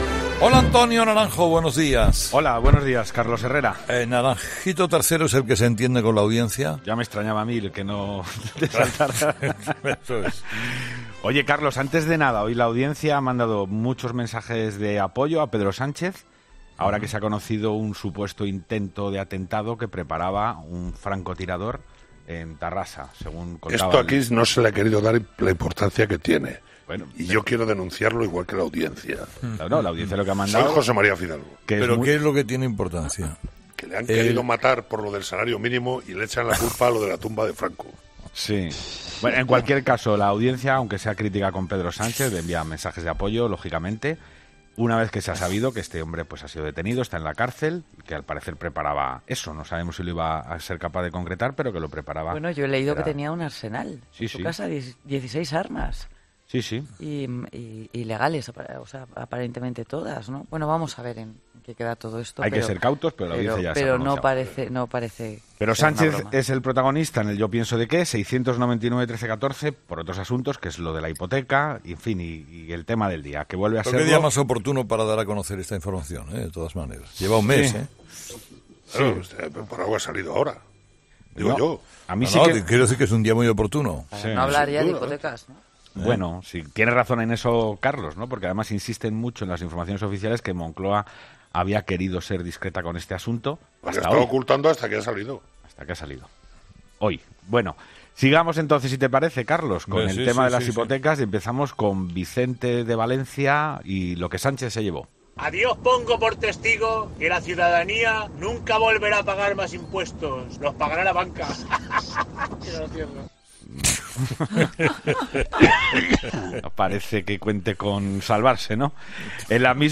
Muchos oyentes han querido dejar su particular visión.